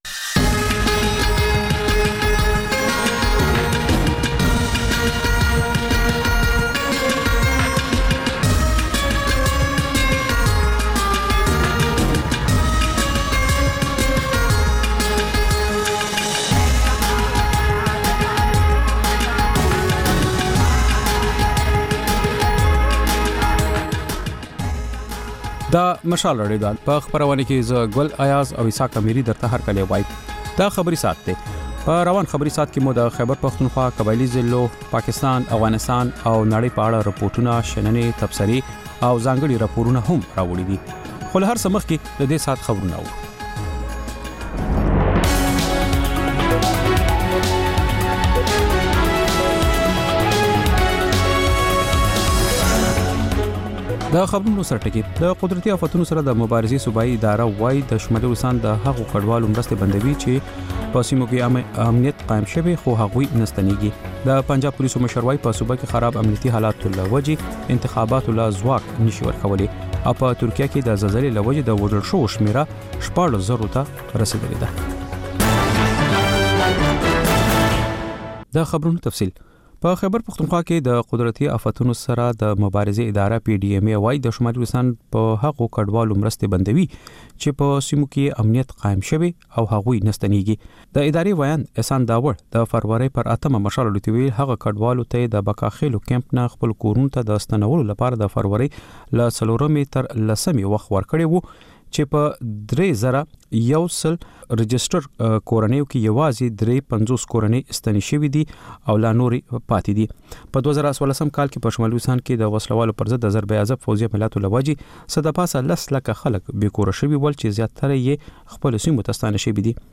دلته د مشال راډیو د سلام پښتونخوا خپرونې تکرار اورئ. په دې خپرونه کې تر خبرونو وروسته رپورټونه خپروو او پکې د سیمې اوسېدونکو د خپلو کلیو او ښارونو تازه او مهم خبرونه راکوي. په خپرونه کې سندرې هم خپرېږي.